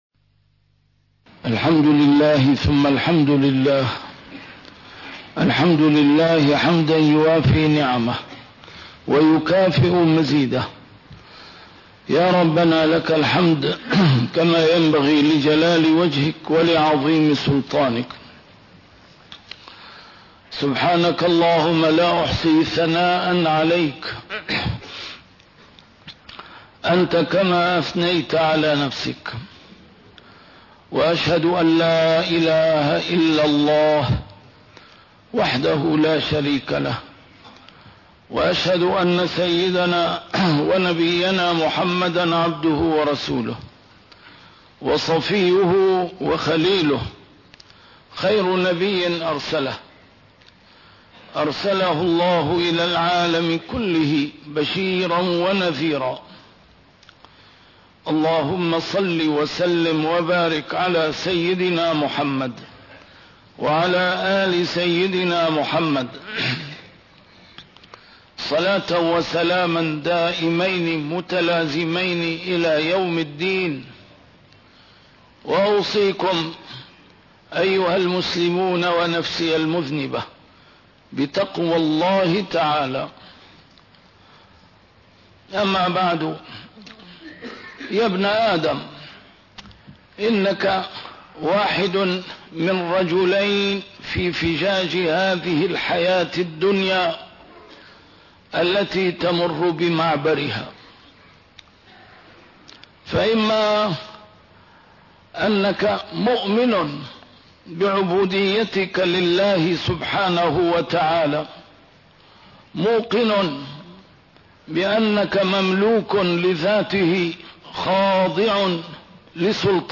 A MARTYR SCHOLAR: IMAM MUHAMMAD SAEED RAMADAN AL-BOUTI - الخطب - يا ابن آدم.. أتتك فرصة العودة إلى صراط ربك